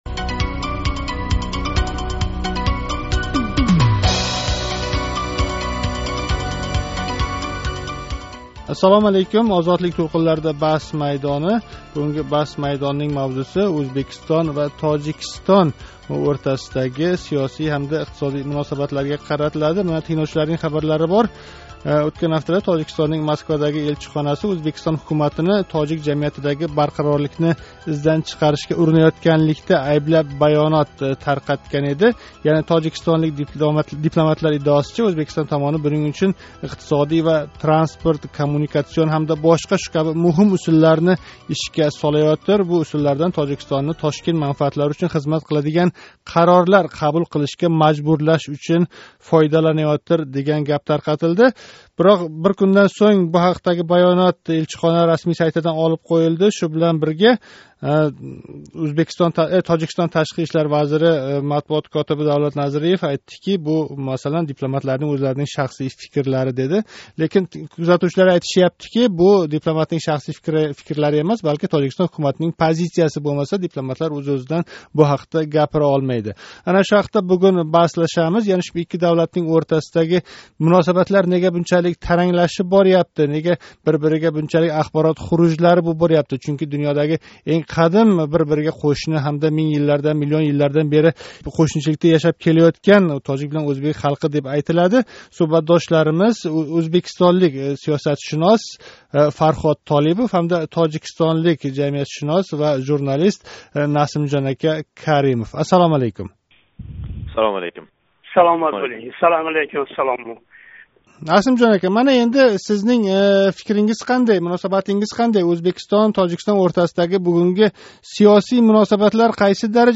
Баҳс майдонида ўзбекистонлик сиёсатшунос ва тожикистонлик жамиятшунос икки қўшни давлатнинг бугунги сиёсий муносабатлари ҳақида баҳслашадилар.